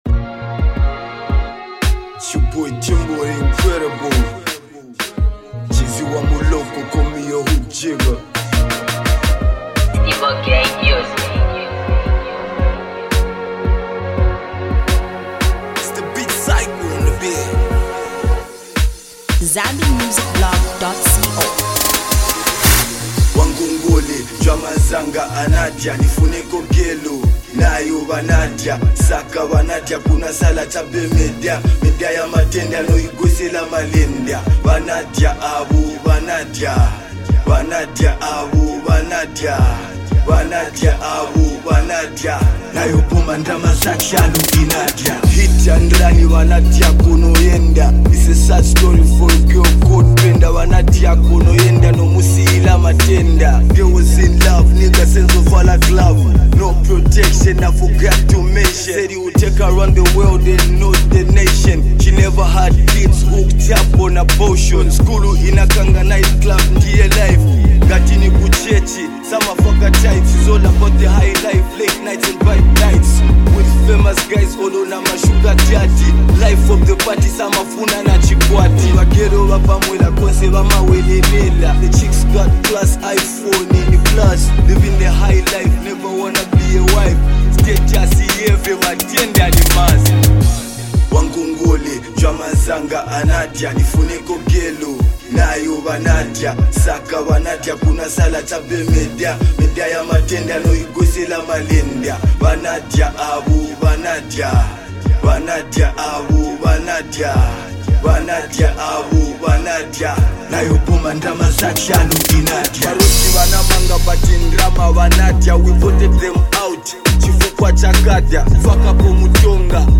raw emotion and swagger into his delivery